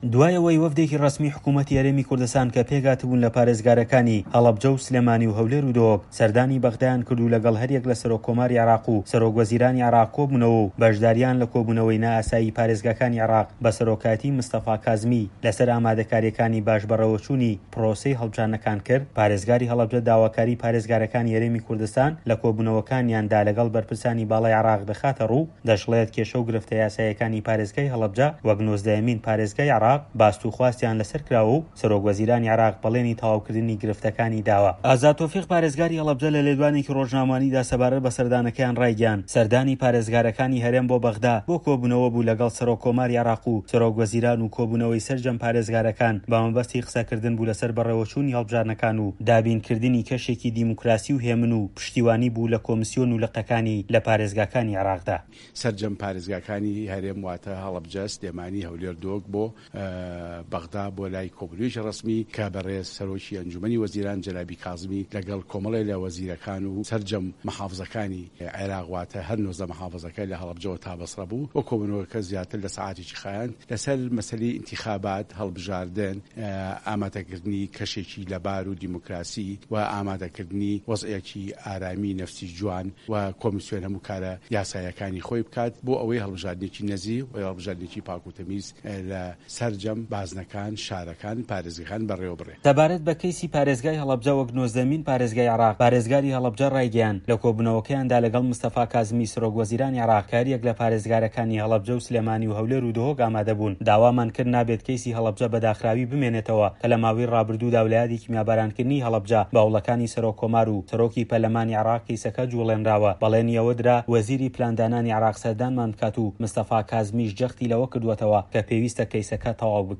ڕاپۆرتی